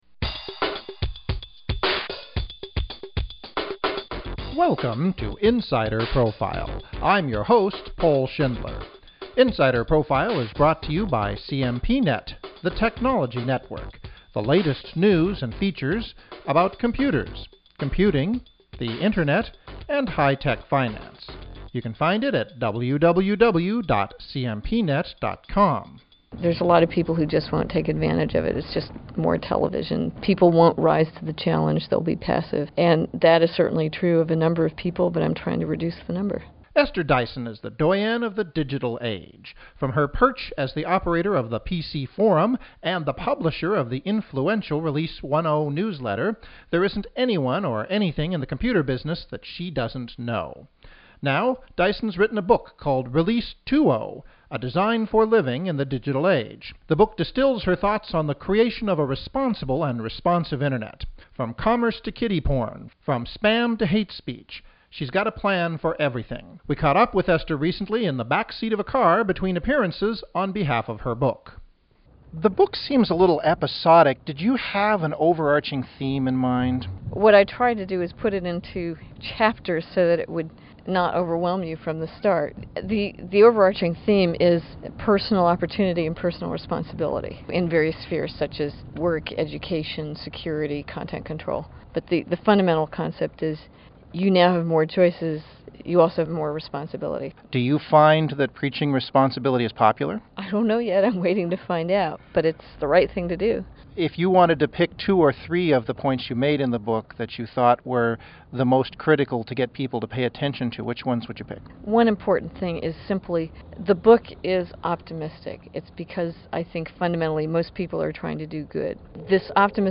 for a backseat interview between appointments.